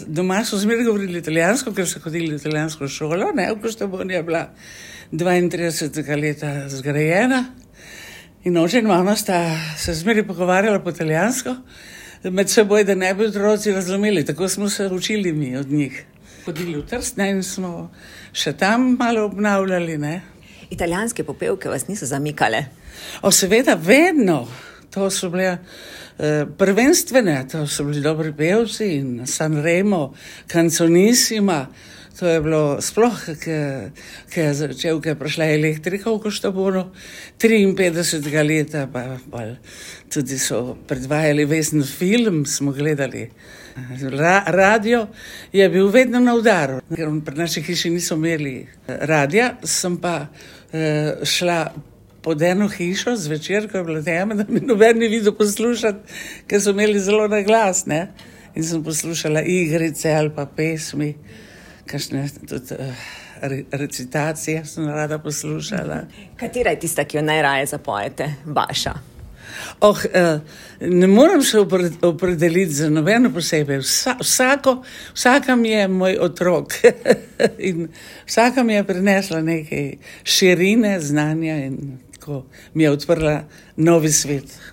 Že zelo mlada je začutila, da so glasbeni odri njena prihodnost, živi v Ljubljani, kot Primorka pa ponosno govori svoj dialekt, že od malega  tudi italijansko: